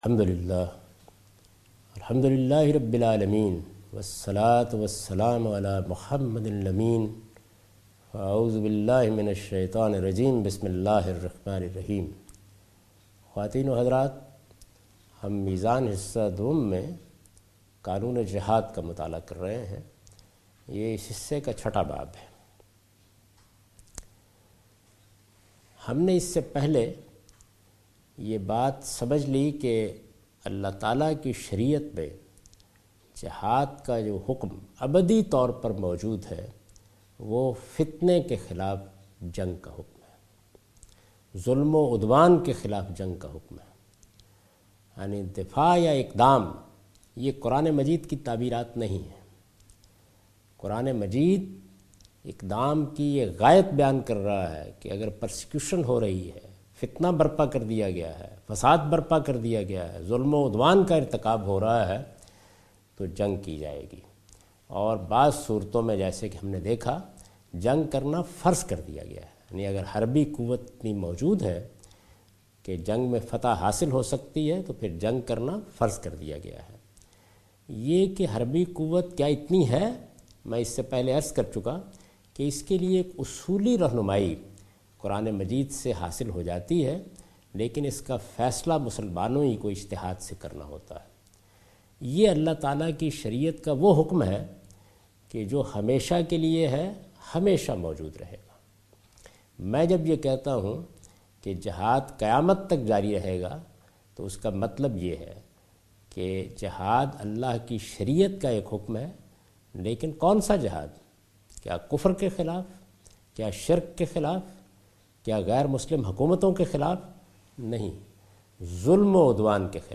A comprehensive course taught by Javed Ahmed Ghamidi on his book Meezan.